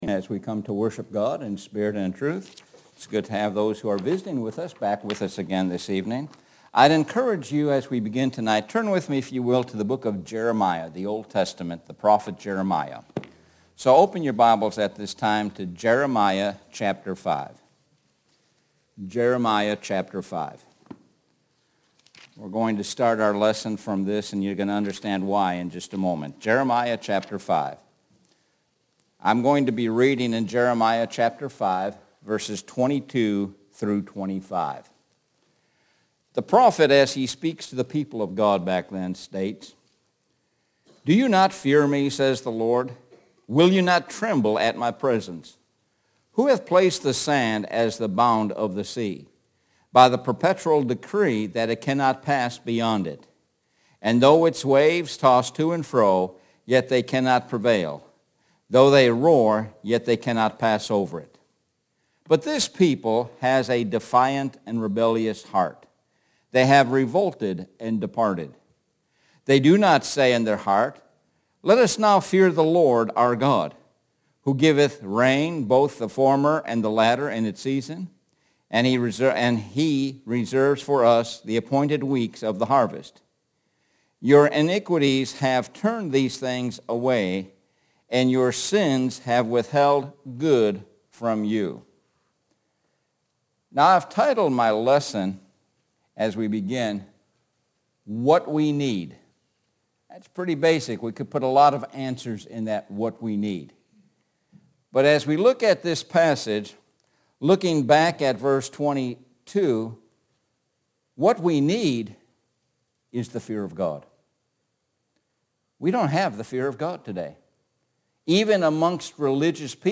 Sun PM Sermon – What the world needs now